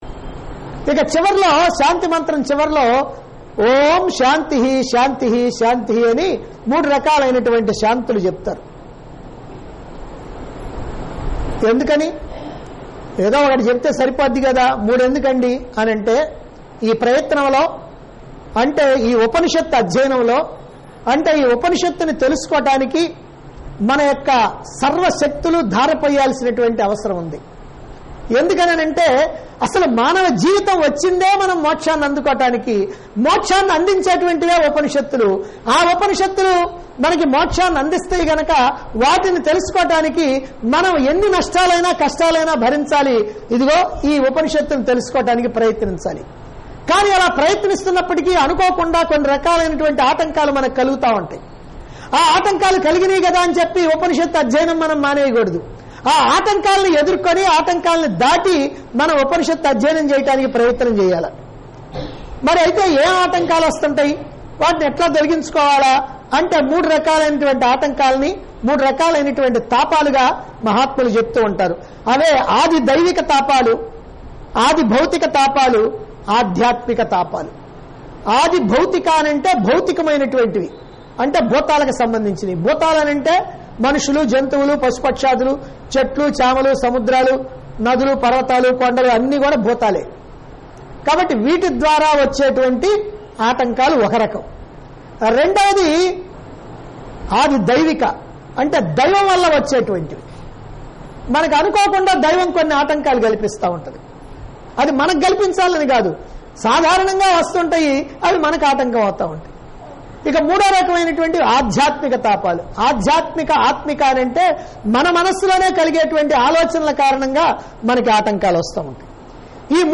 Discourse Conducted At Chilakaluripet, Guntur Dt. Andhra Pradesh.